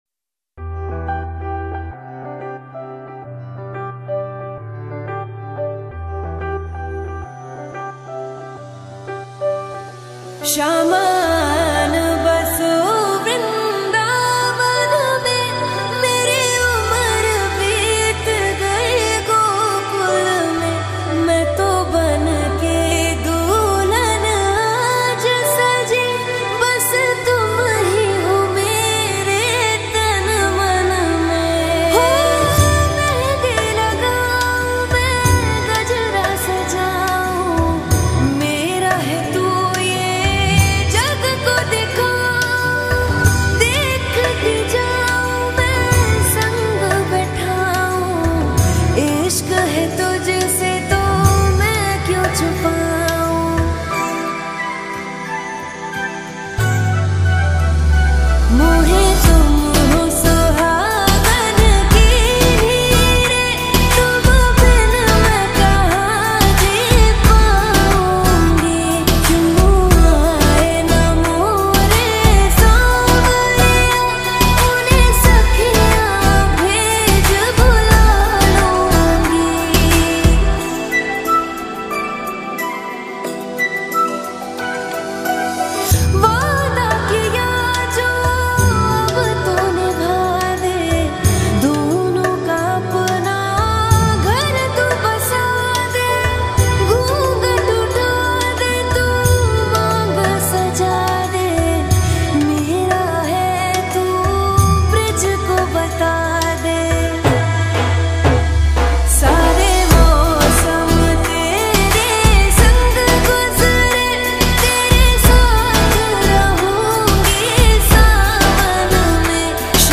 Devotional Songs